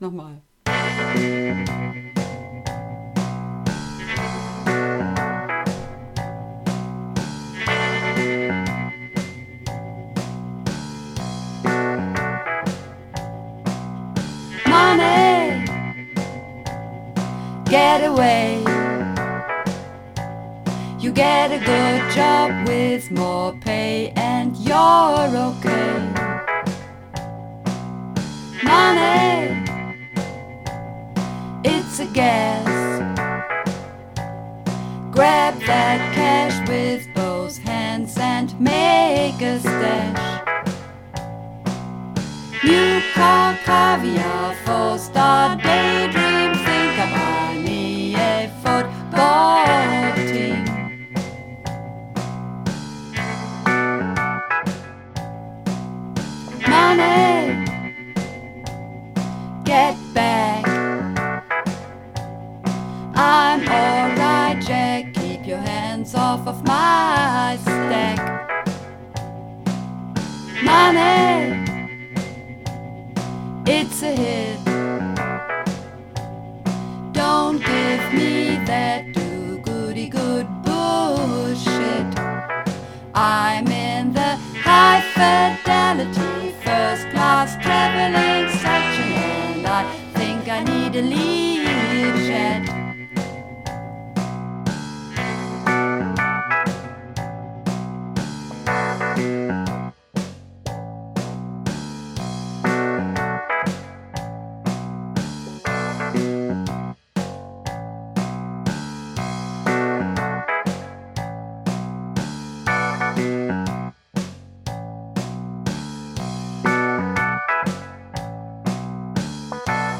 Übungsaufnahmen - Money
Money (Mehrstimmig)
Money__3_Mehrstimmig.mp3